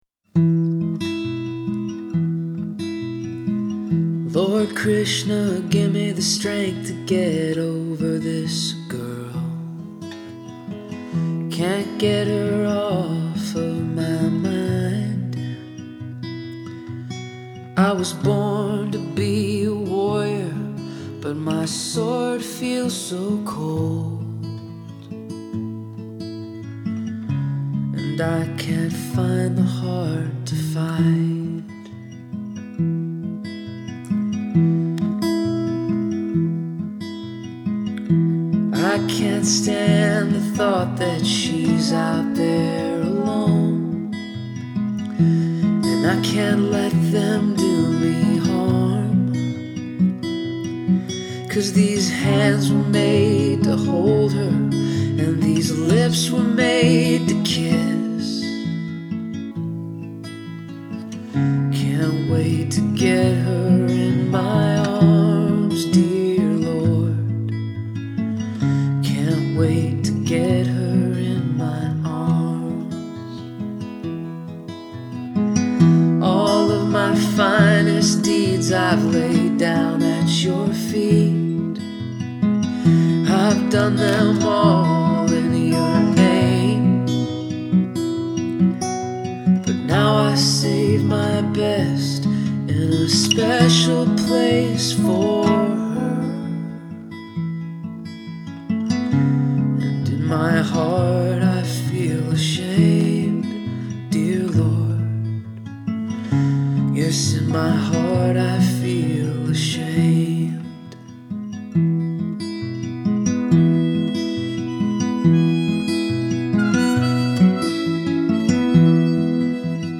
I overdubbed harmonies.